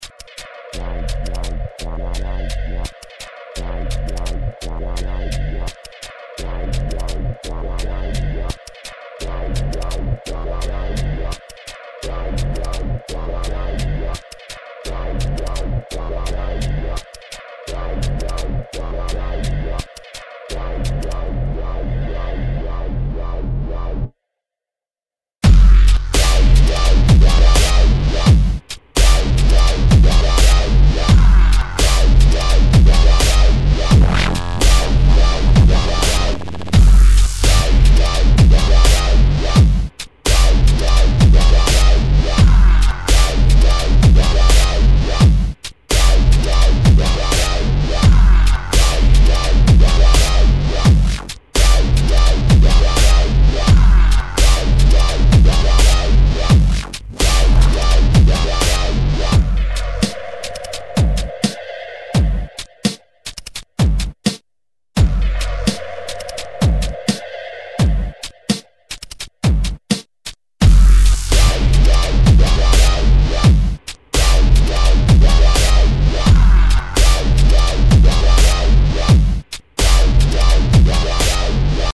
Forum: Dubs & Tunes for Feedback
this one is pretty heavy :